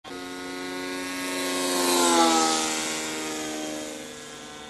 Im Vergleich zum Pibros-400 ist diese Motorisierung deutlich leiser, man hört aber auch hier das "Propellerpeitschen" durch die Einbaulage des Antriebs heraus.
MP3-Schnipsel "Schneller Vorbeiflug"